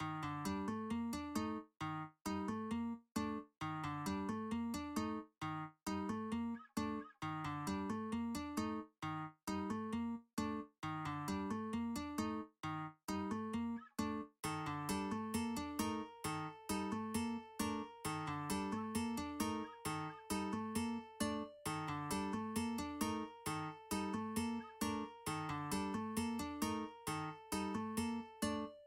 标签： 133 bpm Trap Loops Guitar Acoustic Loops 4.87 MB wav Key : Unknown FL Studio
声道立体声